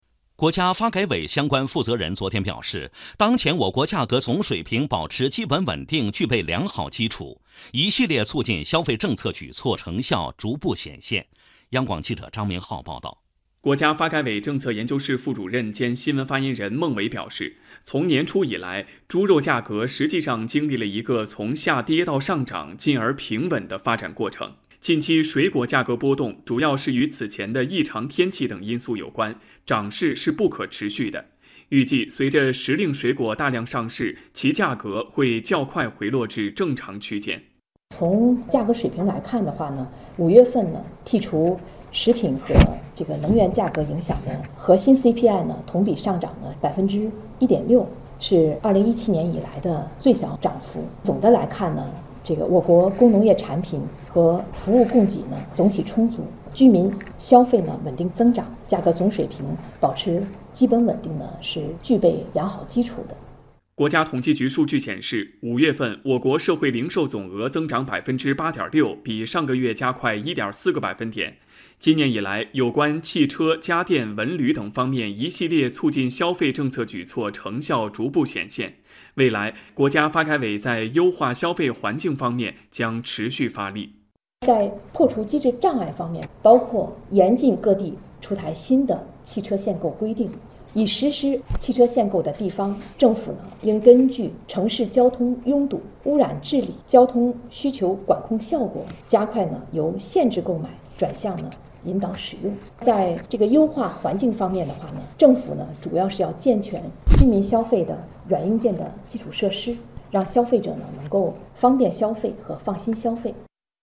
2019-06-18 新闻和报纸摘要全文>>>